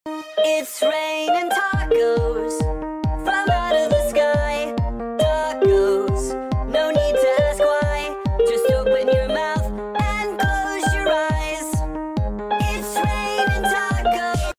Piano Tutorial